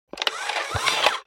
جلوه های صوتی
دانلود صدای ربات 39 از ساعد نیوز با لینک مستقیم و کیفیت بالا